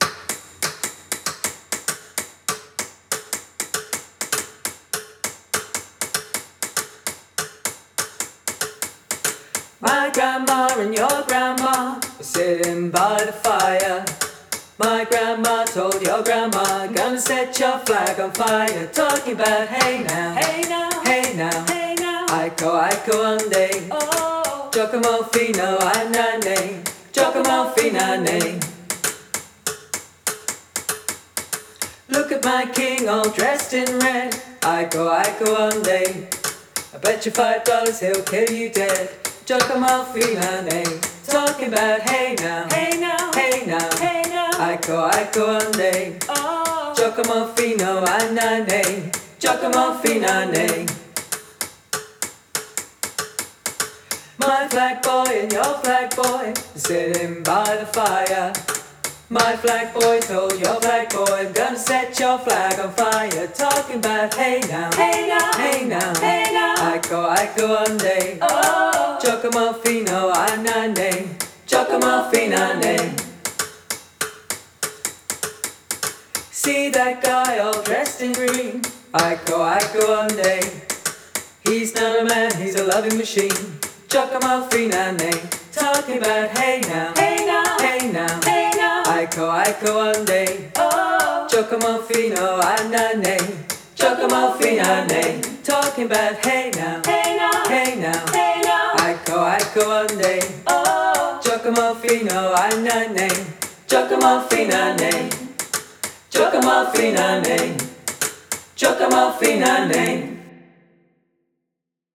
Nice little warm up to get back to covers.